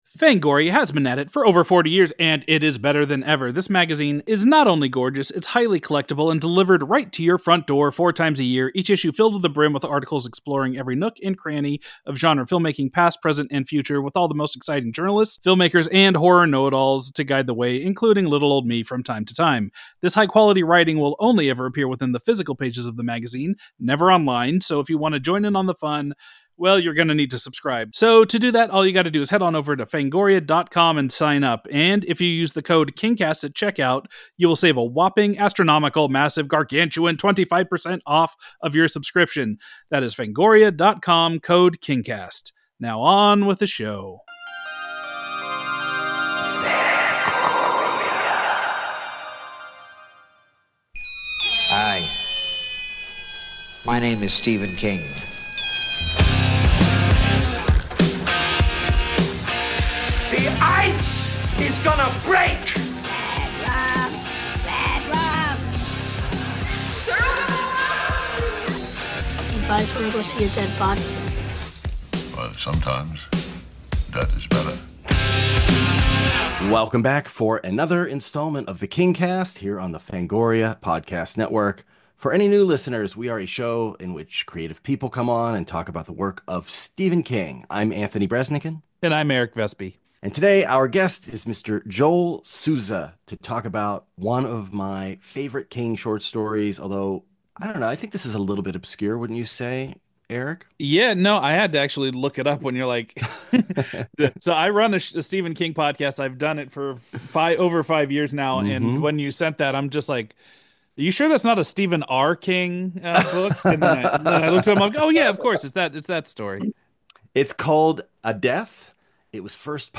Writer/Director Joel Souza joins up to talk about an obscure King short story called "A Death." Souza also opens up about the tragedy behind his latest film, Rust, and discusses why he finished the film after the shocking shooting incident that took the life of his cinematographer, Halyna Hutchins.
So, we have some deep, sad conversations sprinkled throughout with nerdy book and movie talk.